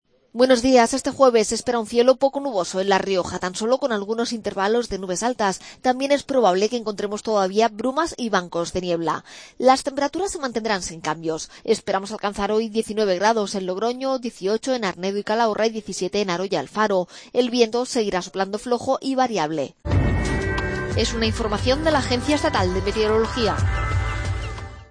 AUDIO: Pronóstico.